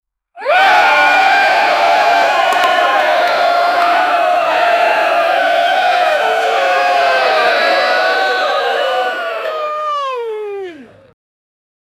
Yay Sound Effects - Free AI Generator & Downloads
a-loud-yay-sound-from-a-crowd-4wjllxep.wav